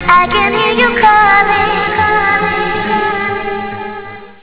zensky hlas (spev?)